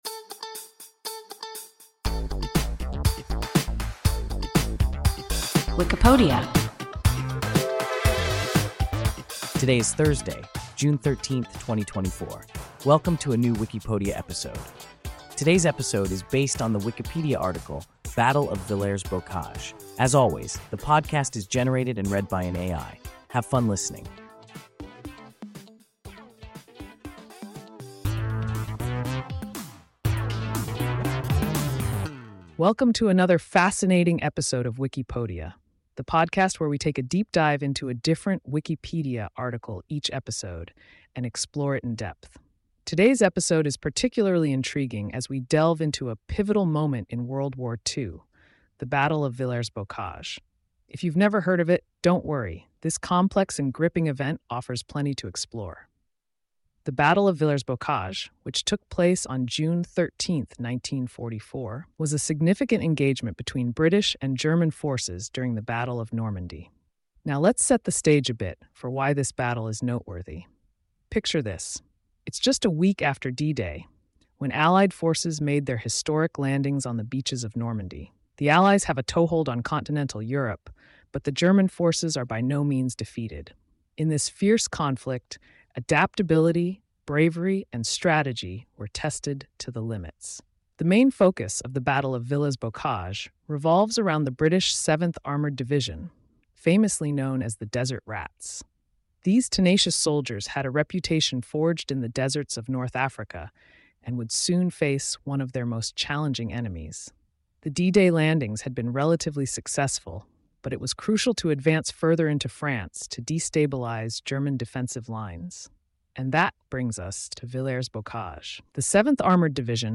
Battle of Villers-Bocage – WIKIPODIA – ein KI Podcast